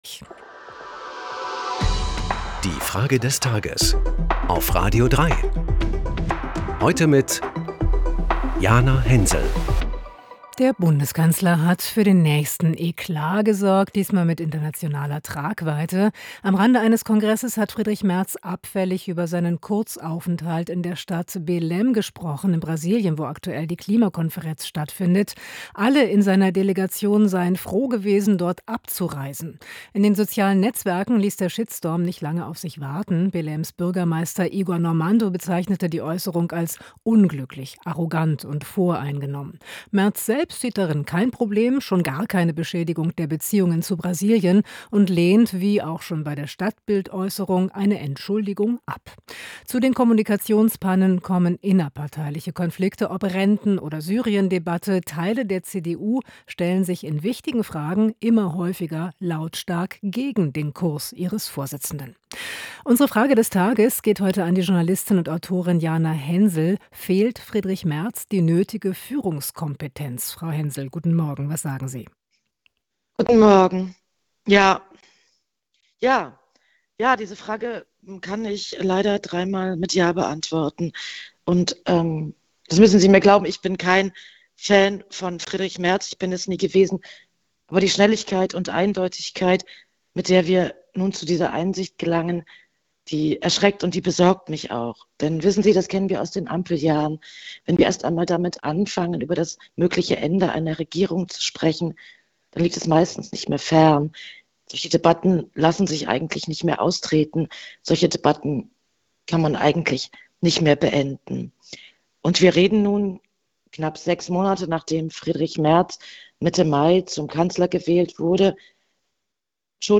Frage des Tages an die Journalistin und Autorin Jana Hensel lautet: